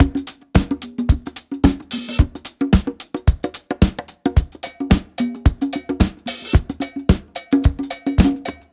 Unison Funk - 10 - 110bpm - Tops.wav